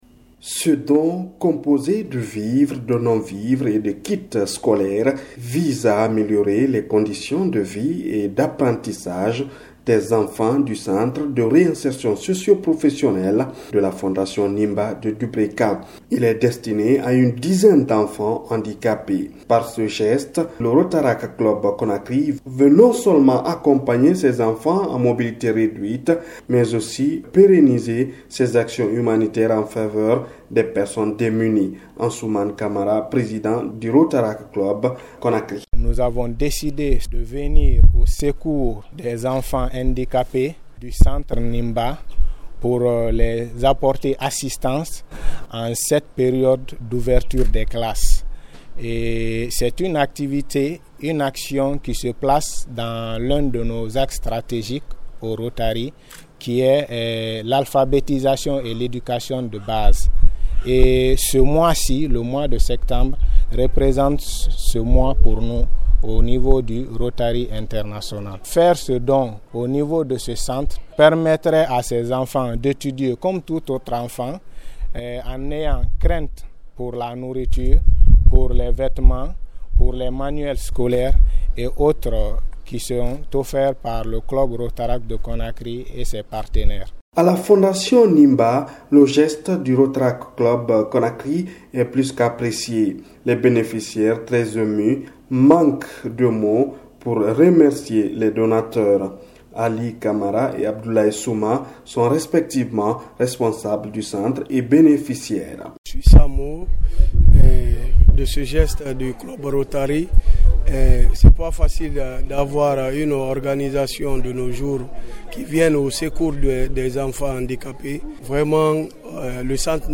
REPORTAGE-ROTARACT-DUBREKA.mp3